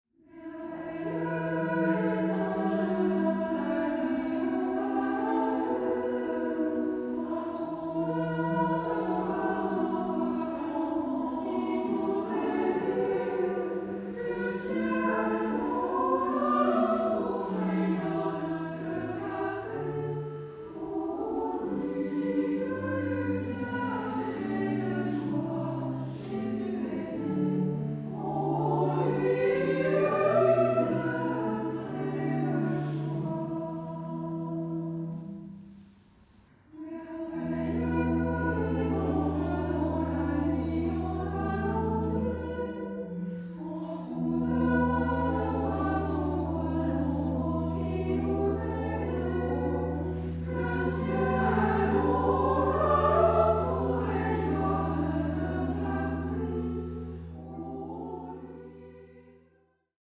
SSAA (4 voices women) ; Full score.
Motet. Christmas carol.
Instruments: Organ (1)